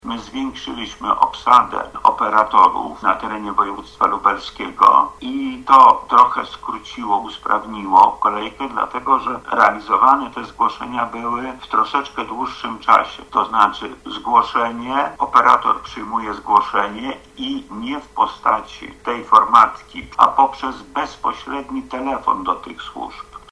• mówi Sprawka.